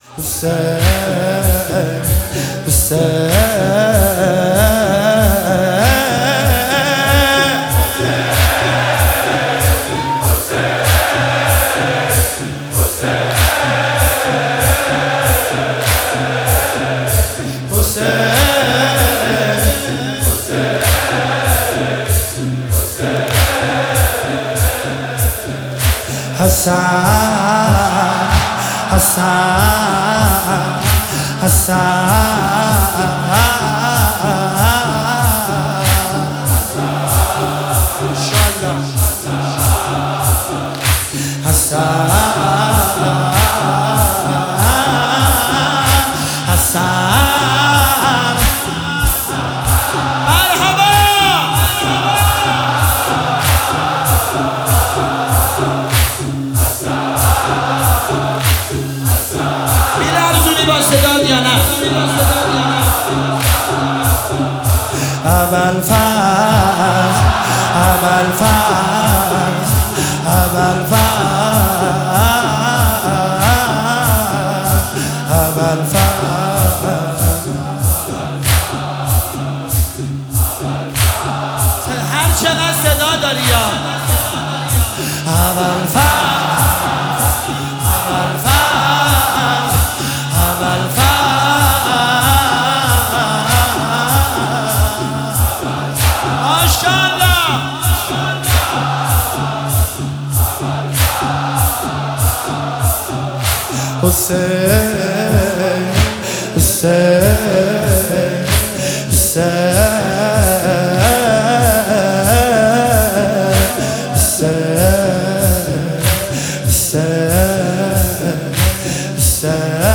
واحد فاطمیه 1403